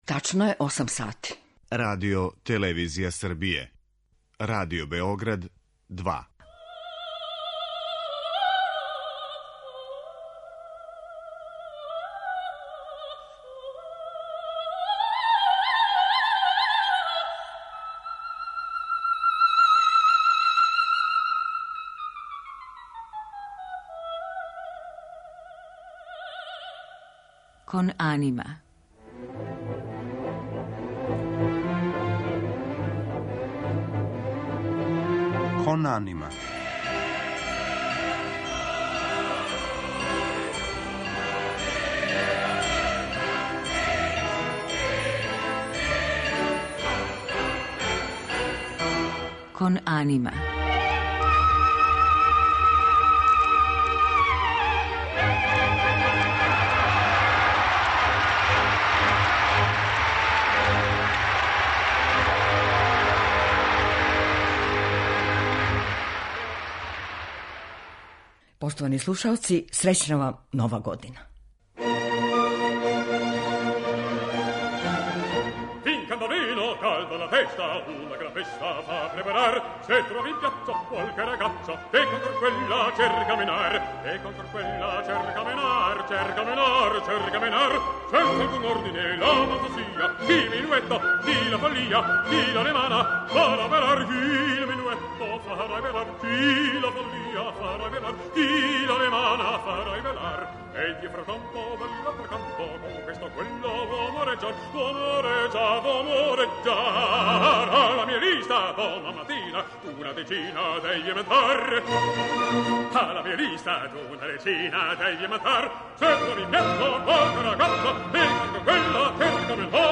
Другог дана нове године, емисија 'Кон анима' доноси избор најпопуларнијих и најлепших оперских арија, препознатљивих сваком слушаоцу.
Данас ћемо емитовати арије из њихових опера у извођењу Џоан Садерленд, Лучана Паваротија, Радмиле Бакочевић, Суми Јо, Жарка Цвејића и других великана оперске уметности.